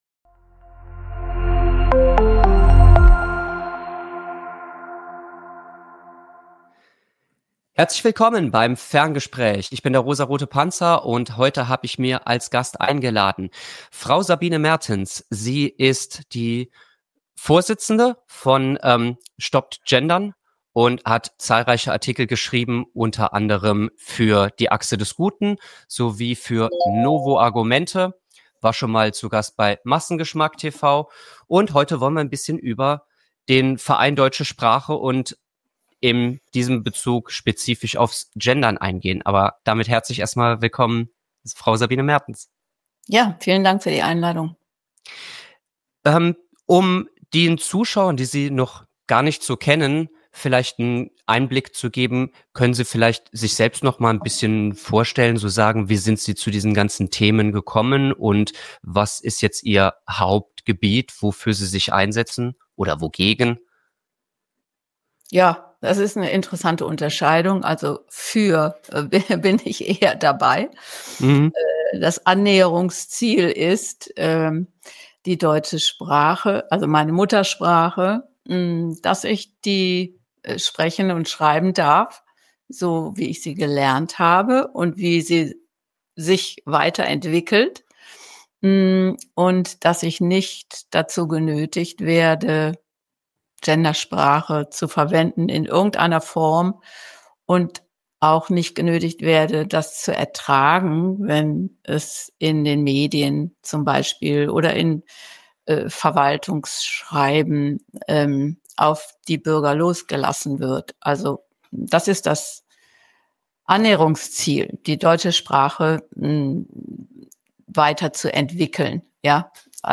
Die beiden nehmen in lockerer Runde unter die Lupe, wie überzogene Sprachvorschriften und staatliche Eingriffe unseren Alltag beeinflussen.